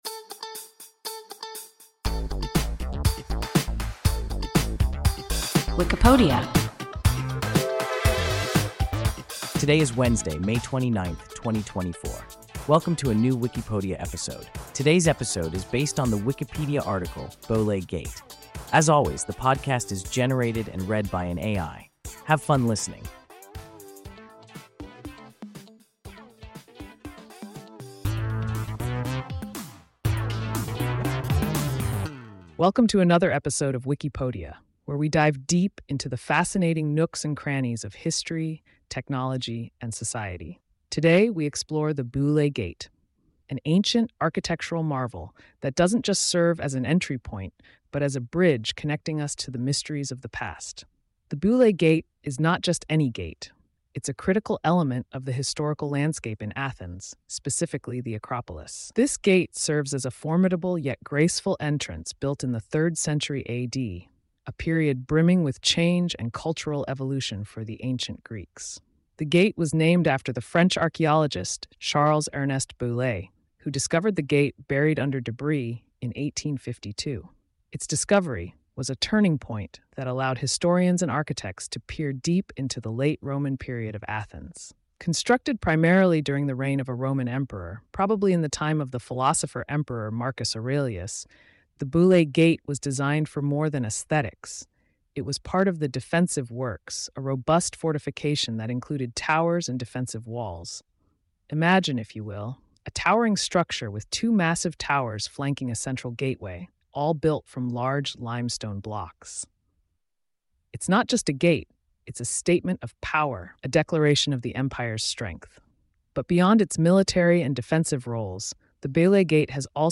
Beulé Gate – WIKIPODIA – ein KI Podcast